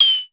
KLONE_PERC388.wav